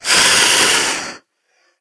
c_wserpent_hit1.wav